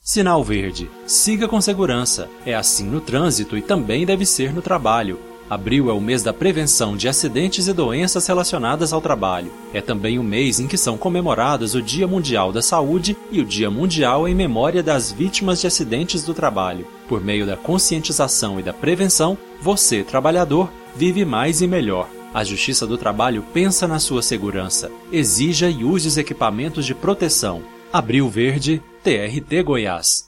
Ouça abaixo os dois spots produzidos pela Rádio Web TRT Goiás para a divulgação da campanha Abril Verde:
abril-verde-spot-1.mp3